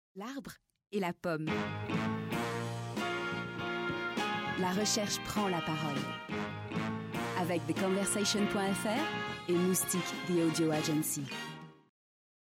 VOIX OFF
16 - 53 ans - Mezzo-soprano